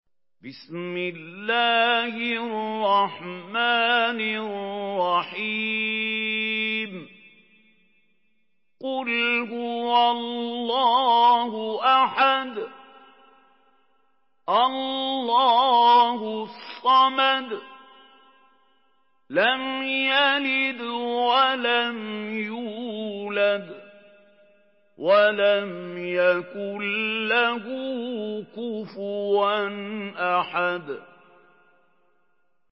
Surah আল-ইখলাস MP3 in the Voice of Mahmoud Khalil Al-Hussary in Hafs Narration
Murattal